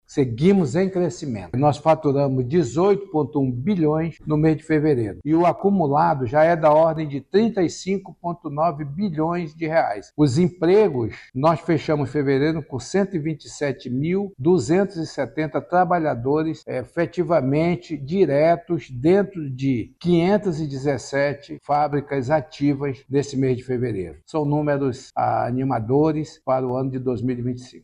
Ainda segundo o superintendente, os números são animadores para o setor industrial do Estado.